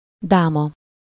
Ääntäminen
France (Paris): IPA: [yn ʁɛn]